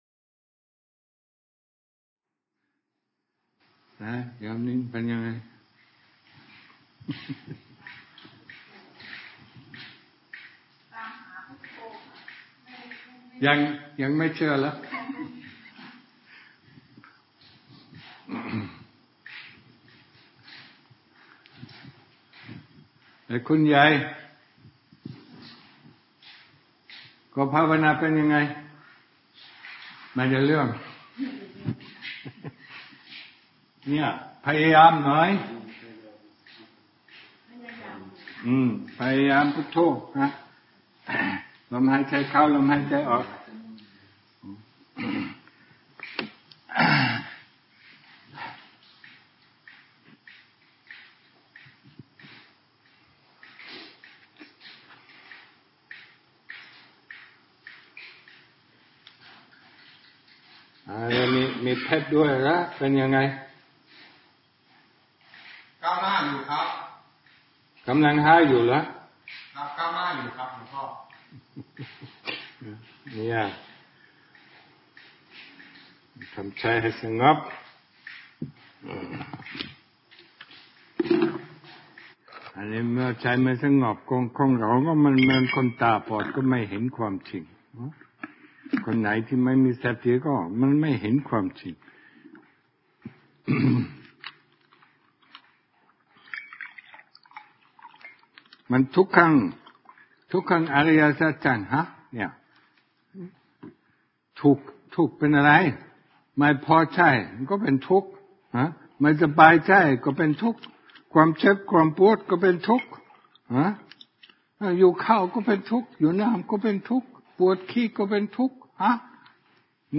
เทศน์อบรมวันออกพรรษา